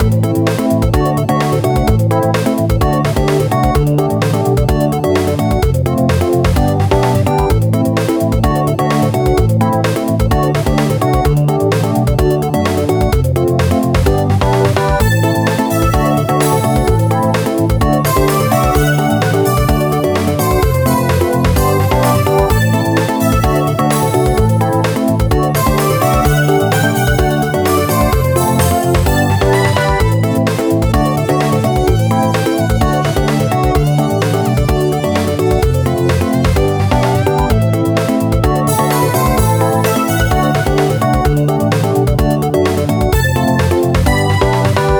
Genre: Game Music
ピコピコ感がそことなく懐かしい雰囲気？